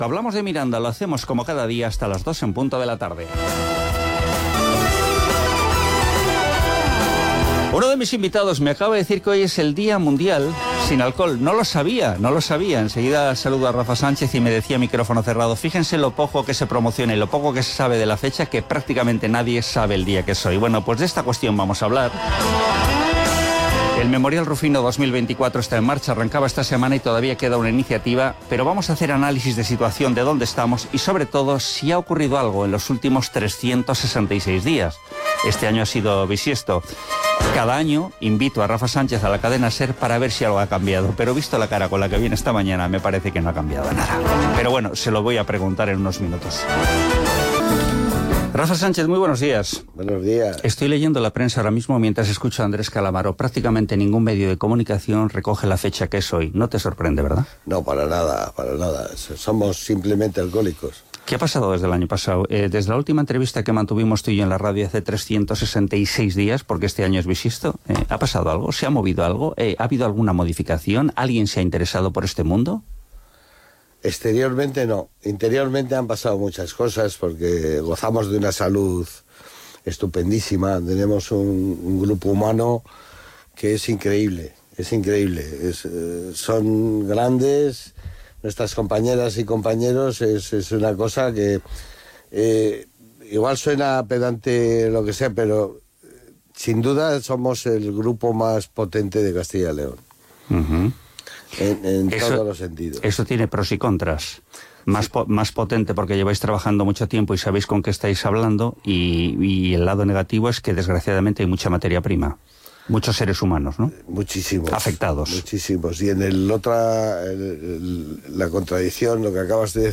Entrevista Miranda FM nov23